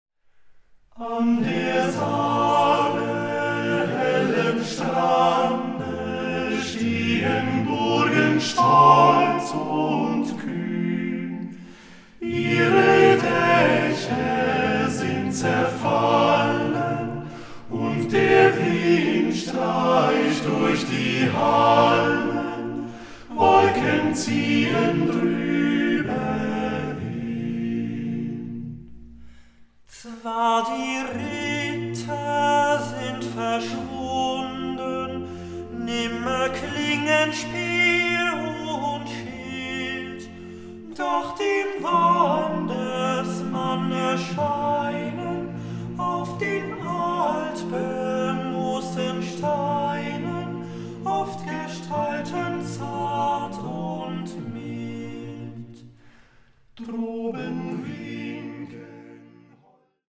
the eight singers revive an entire folk culture!